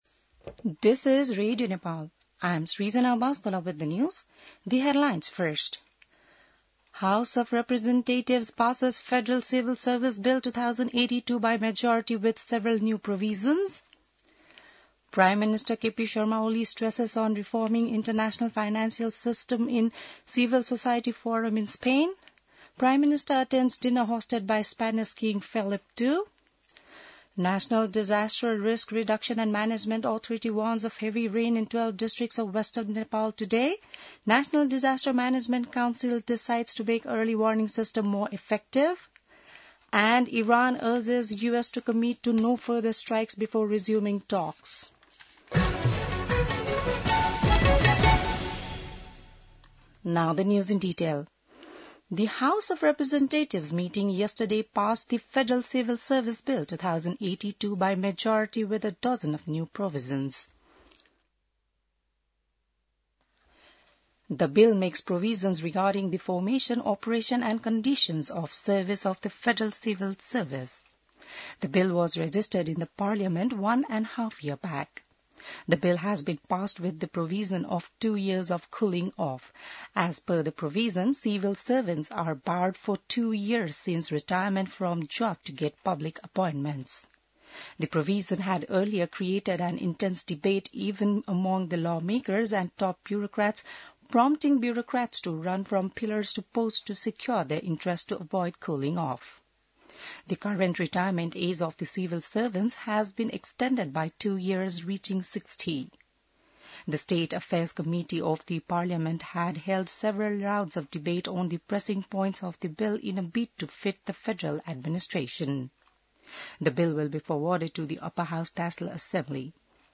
बिहान ८ बजेको अङ्ग्रेजी समाचार : १६ असार , २०८२